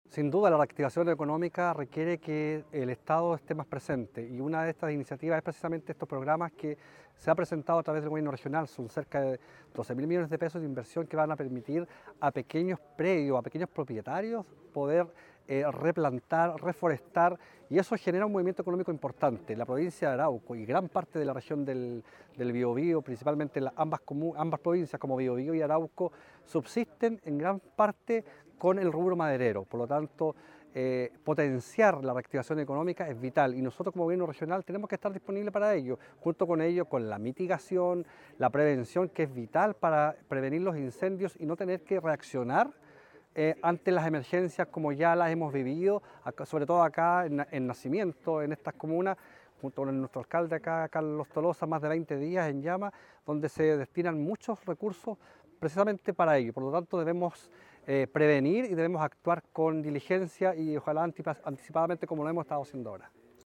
En la misma línea, Cristian Medina, consejero regional de la provincia de Arauco, aseguró que “la reactivación económica requiere que el Estado esté más presente y estos proyectos permitirán a pequeños propietarios poder replantar, reforestar y generará un movimiento económico importante”.